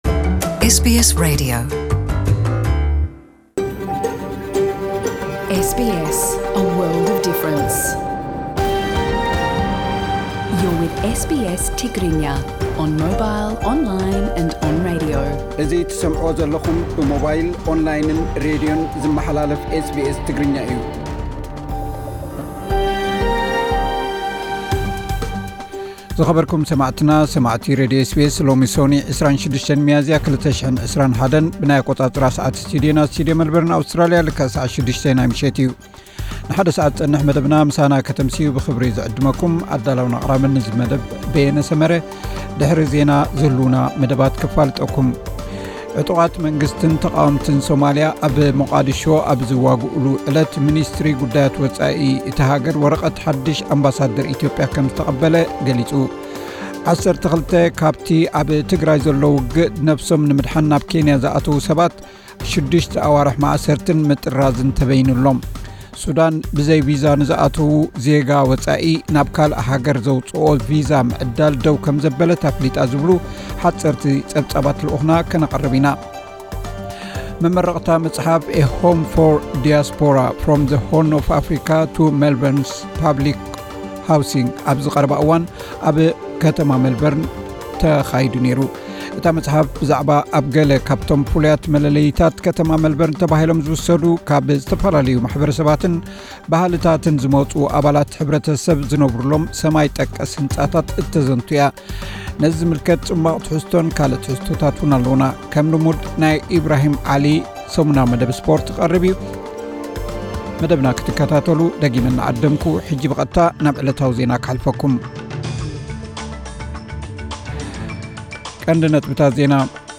ዕለታዊ ዜና ኤስቢኤስ ትግርኛ (26/04/2021)